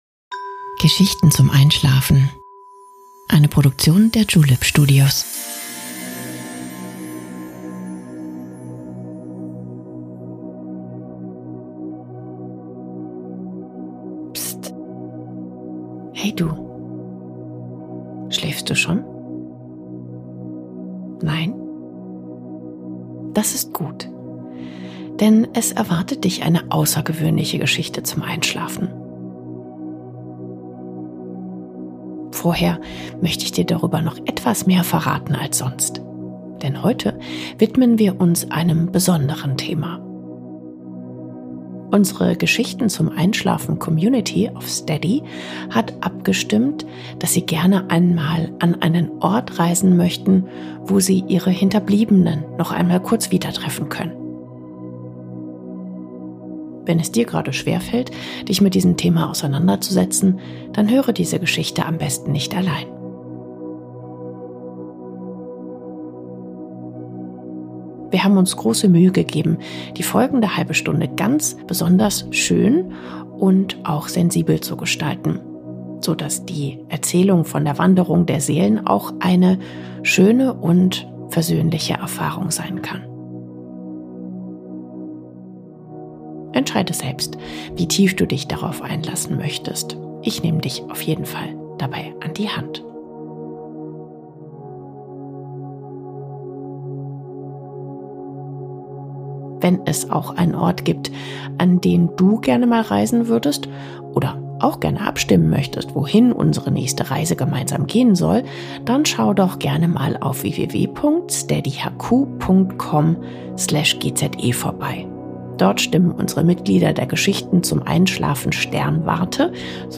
Unsere Geschichten sollen dir dabei helfen, zur Ruhe zu kommen und langsam in einen verdienten und erholsamen Schlaf zu gleiten.